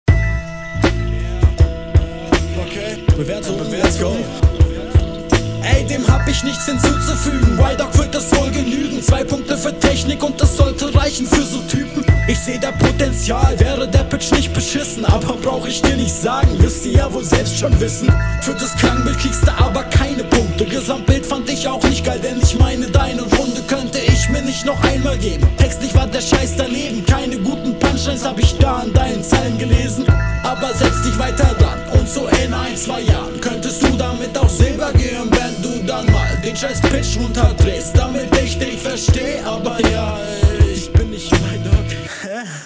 Aber sonst nicer Flow